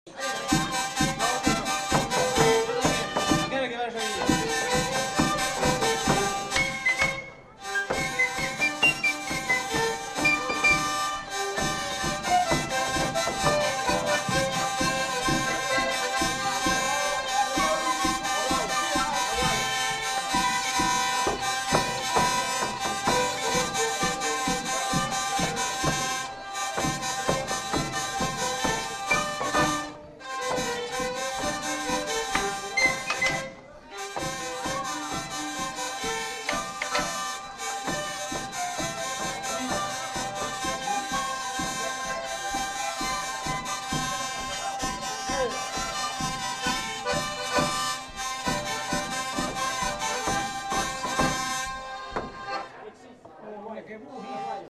Aire culturelle : Gabardan
Lieu : Créon-d'Armagnac
Genre : morceau instrumental
Instrument de musique : vielle à roue ; violon ; percussions ; flûte à bec
Danse : marche (danse)